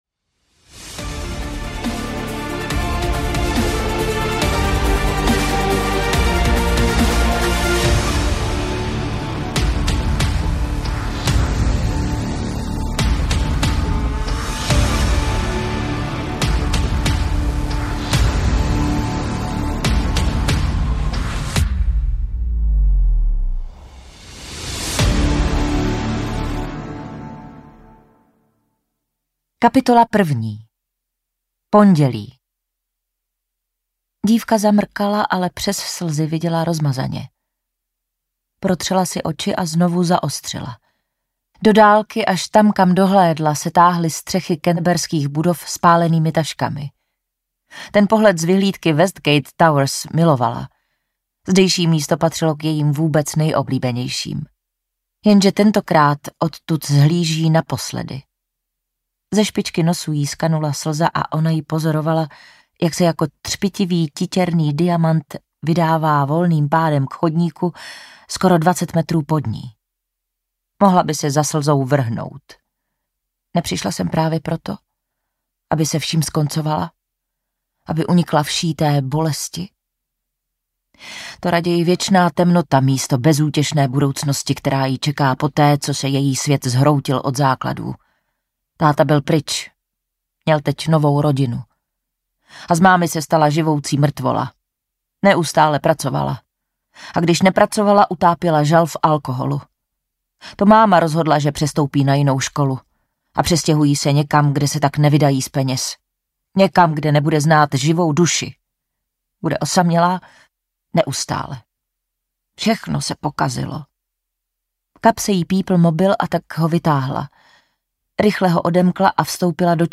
Poslední výkřik audiokniha
Ukázka z knihy
• InterpretKlára Cibulková